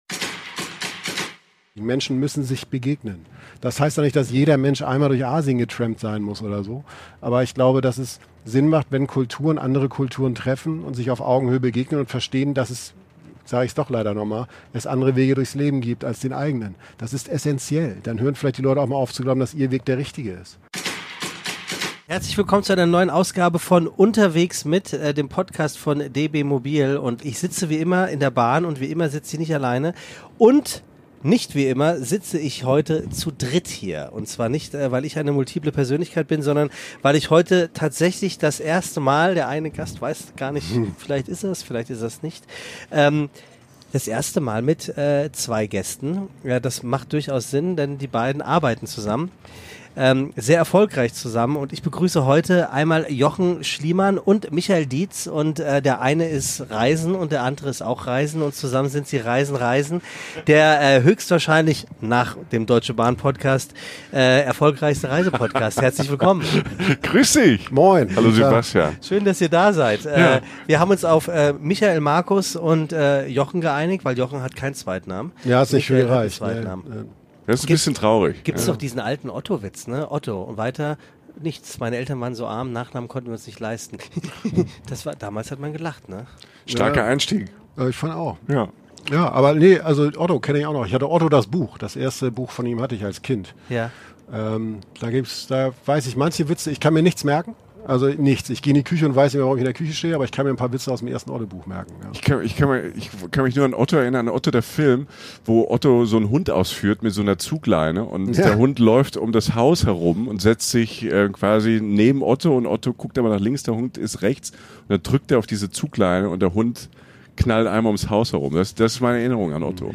Ein lustiges, inspirierendes Gespräch über den Unterschied zwischen Urlaub und Reisen, Abenteuer vor der Haustür und über einen Koffer als ungewolltes Geschenk.